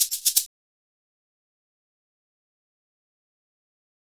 Index of /90_sSampleCDs/USB Soundscan vol.56 - Modern Percussion Loops [AKAI] 1CD/Partition D/04-SHAKER119